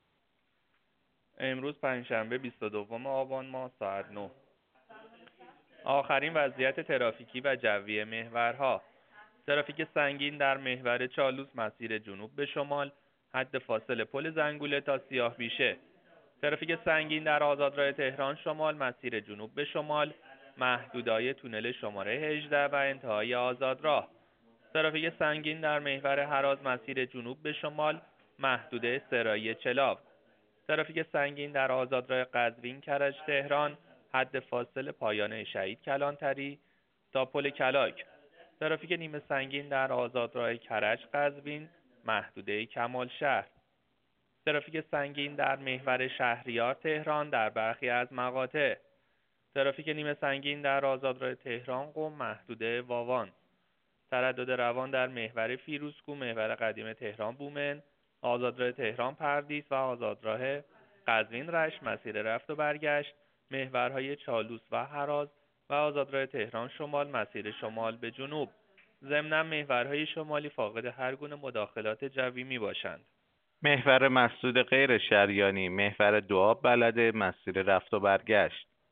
گزارش رادیو اینترنتی از آخرین وضعیت ترافیکی جاده‌ها ساعت ۹ بیست و دوم آبان؛